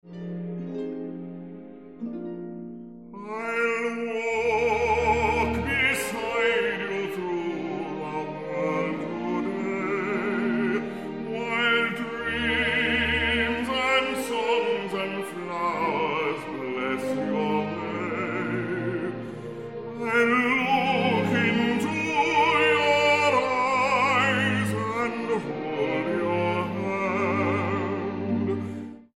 Bass Baritone